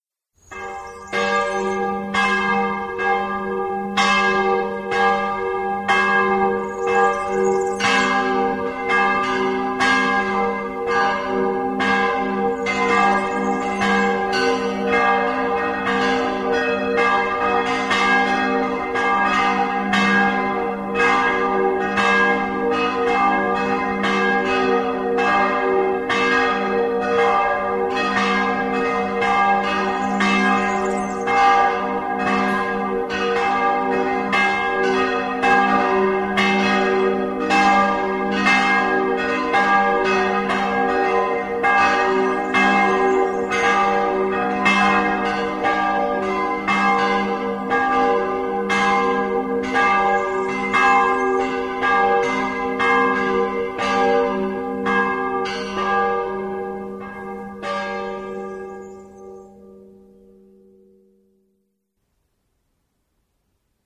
télécharger sur votre portable ces cloches monastiquesSonnerie: Cloches, appel à la prière pour votre tél portable
Cloches.mp3